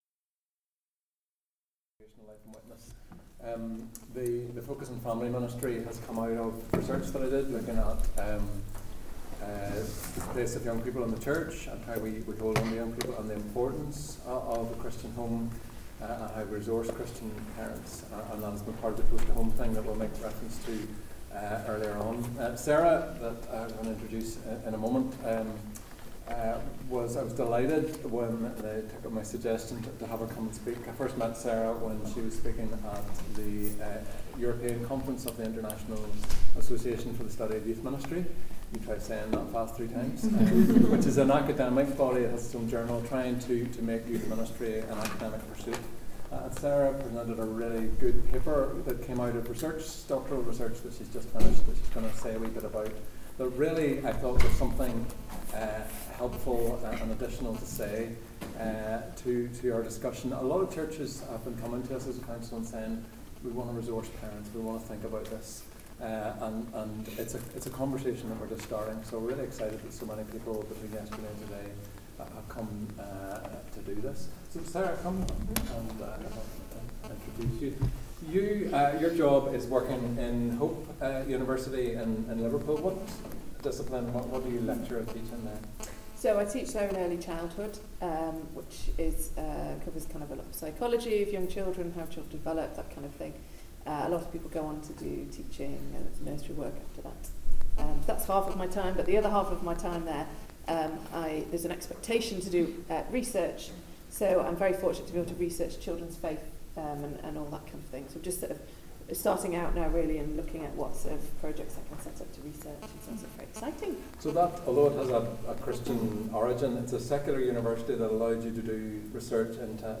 This seminar will explore these issues, enabling churches to consider how biblical principles and research findings may be practically applied in individual